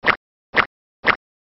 SFX跳绳时甩绳的声音音效下载
这是一个免费素材，欢迎下载；音效素材为跳绳时甩绳的声音， 格式为 mp3，大小1 MB，源文件无水印干扰，欢迎使用国外素材网。